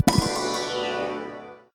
explosion_release1.ogg